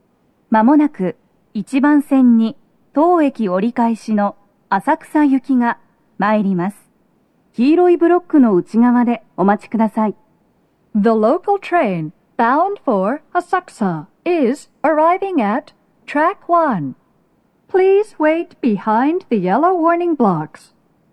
スピーカー種類 TOA弦型、TOA天井型() ※収録音声は全てTOA弦型での収録。
🎵接近放送
鳴動は、やや遅めです。
女声
gshibuya1sekkin.mp3